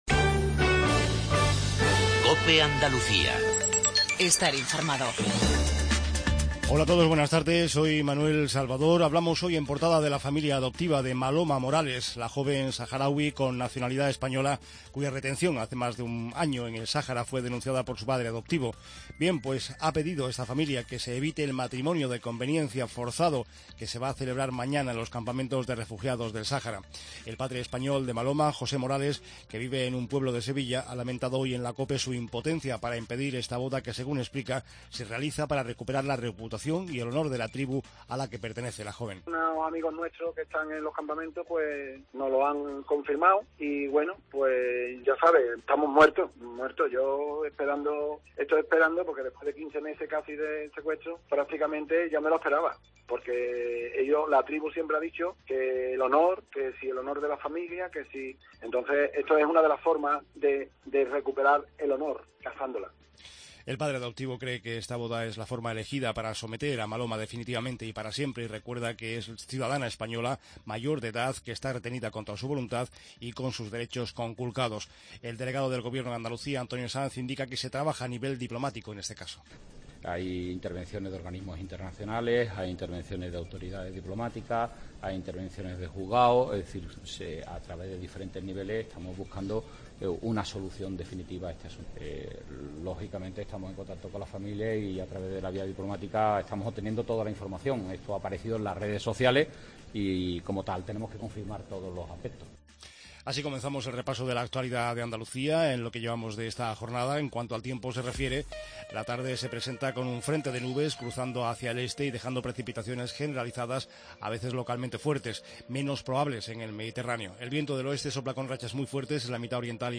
INFORMATIVO REGIONAL MEDIODIA